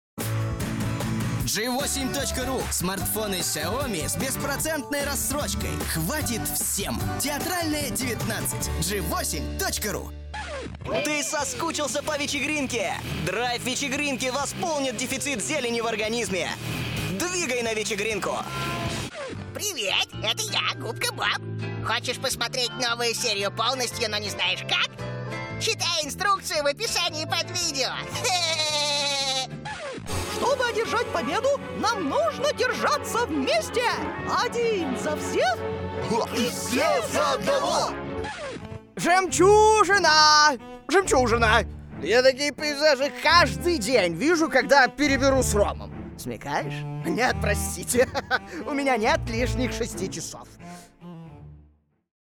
Тракт: Тракт: Микрофон: SHURE SM7B; Пред: LONG Voice Master; Карта (АЦП): Steinberg UR22mkII
Необычный, нестандартный.